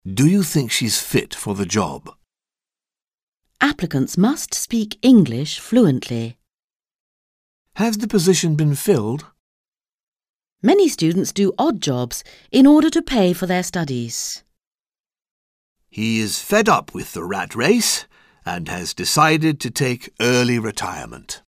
Un peu de conversation - Le travail : conflits et solutions